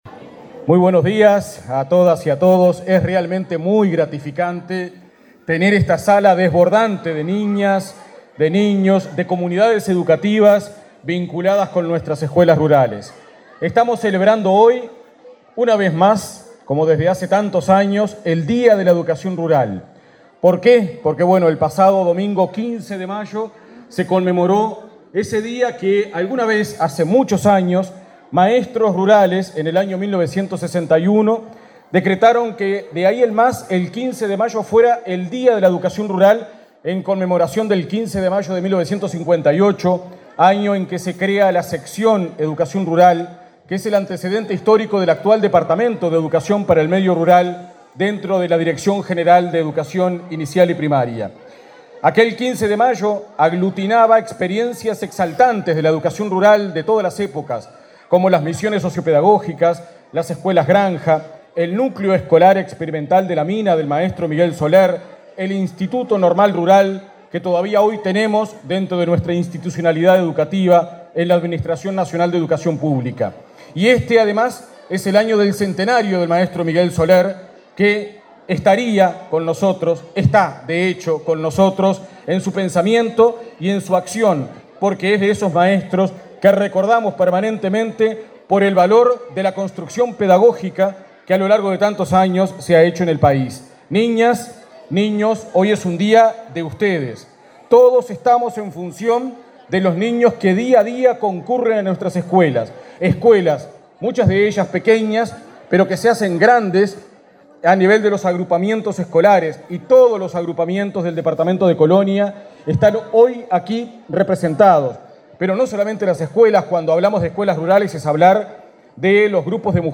Palabras de autoridades en el acto por el Día de la Educación Rural
Palabras de autoridades en el acto por el Día de la Educación Rural 31/05/2022 Compartir Facebook X Copiar enlace WhatsApp LinkedIn El director departamental de Educación Rural, Limber Santos; la directora de Primaria, Graciela Fabeyro, y el presidente de la ANEP, Robert Silva, participaron, este martes 31 en Colonia, en el acto por el Día de la Educación Rural.